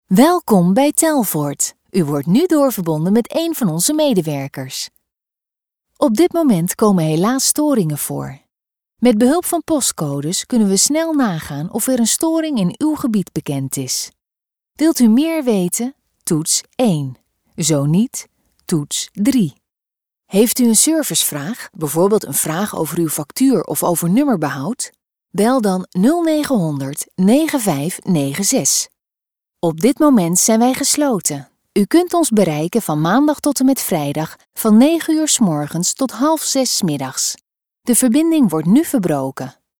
Commerciale, Fiable, Amicale, Chaude, Corporative
Téléphonie